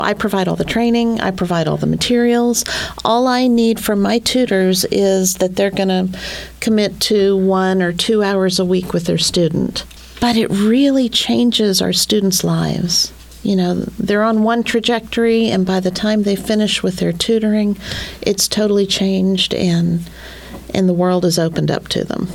a recent guest of the Talk of the Town on KTLO-FM